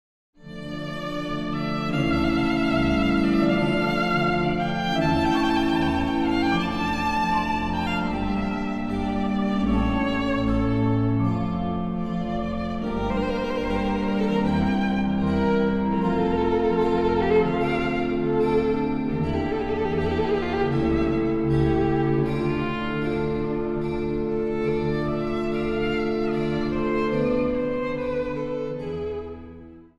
Instrumentaal | Dwarsfluit
Instrumentaal | Synthesizer
Instrumentaal | Viool